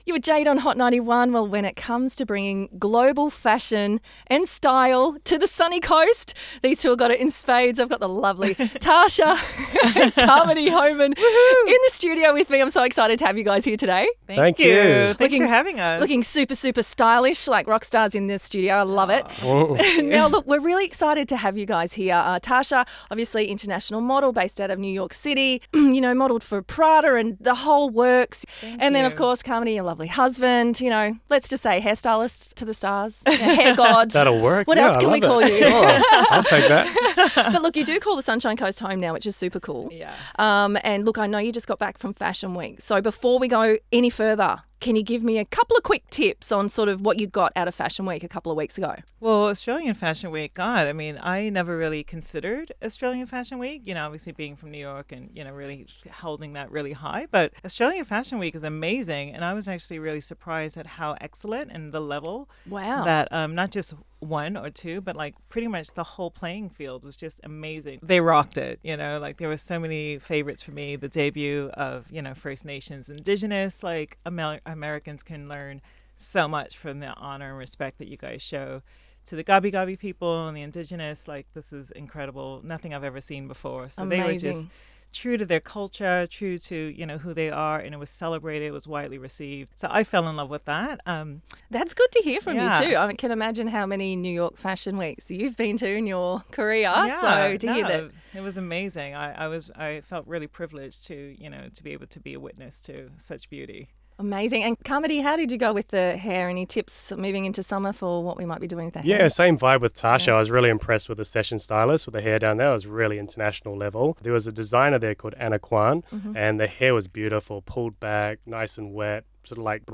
Listen to our radio interview where we talked to Hot 91.1 FM about our take on the Afterpay Australian Fashion Week.